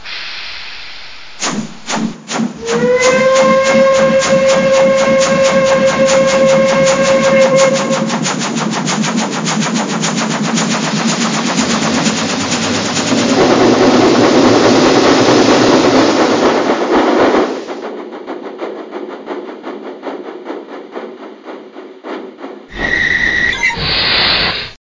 Digitalfunktionen: Fahrsound mit umfangreichen Soundfunktionen, Rauchsatzkontakt digital schaltbar, dieses Fahrzeug mit mfx Decoder meldet sich an einer mfx fähigen Digitalzentrale selbst an zum Beispiel an der Mobile Station von Märklin, unterstützt das DCC Datenformat